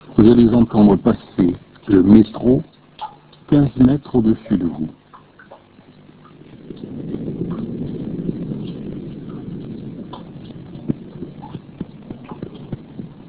Ecoutez le metro d'en dessous